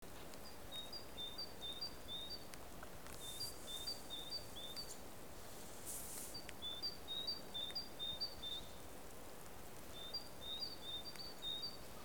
PARMAJspiew1.mp3